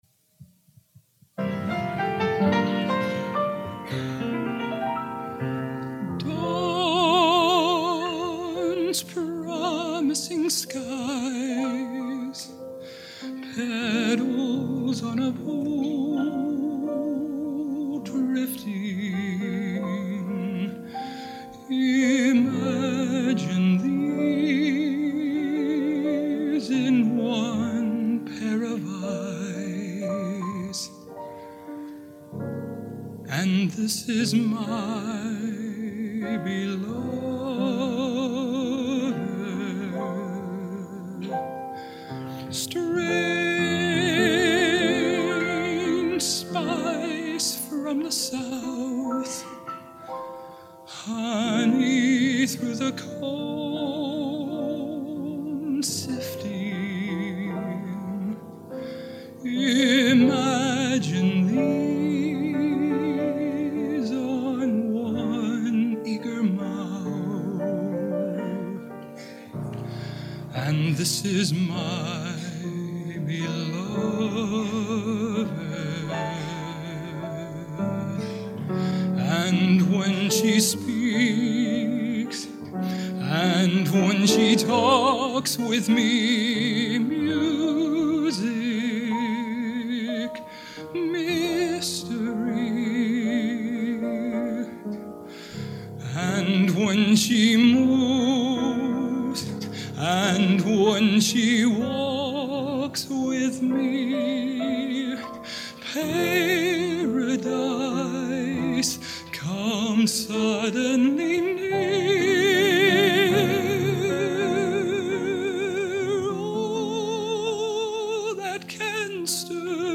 Genre: Broadway Schmalz | Type: End of Season